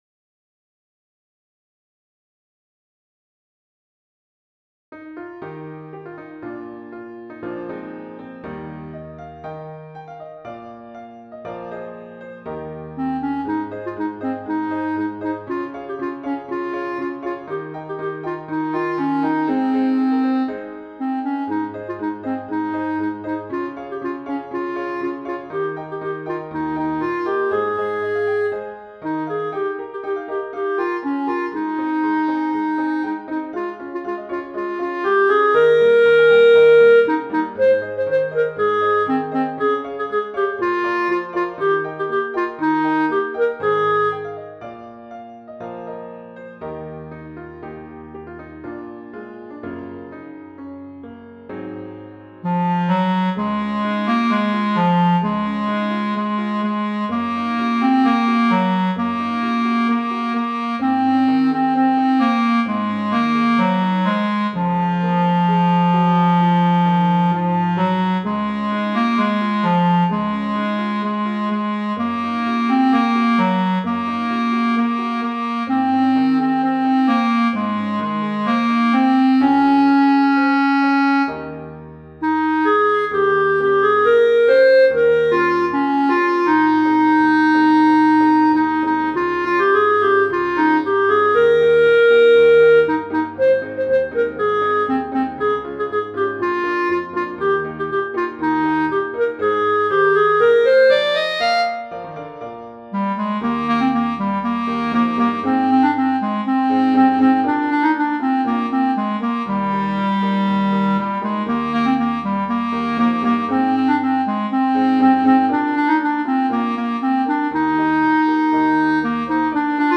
Intermediate Instrumental Solo with Piano Accompaniment.
Christian, Gospel, Sacred.
set to a fast past, energetic jig.